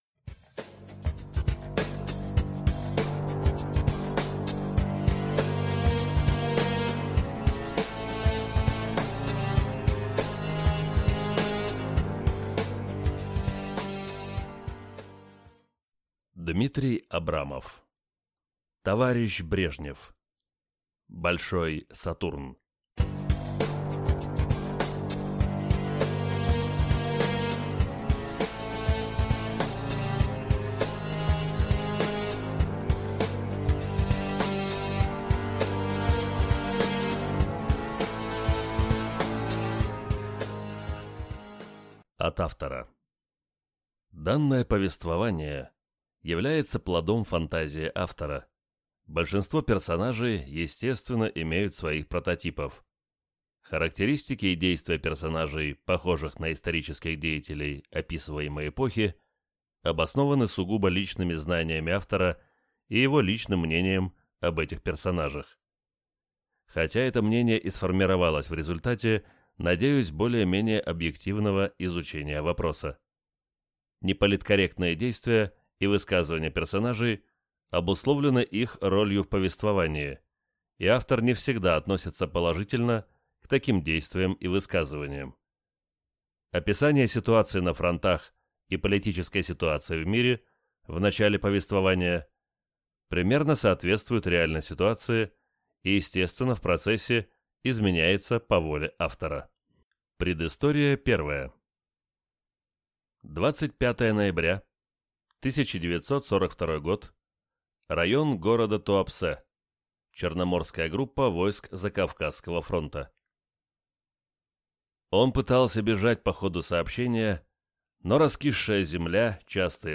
Аудиокнига Товарищ Брежнев. Большой Сатурн | Библиотека аудиокниг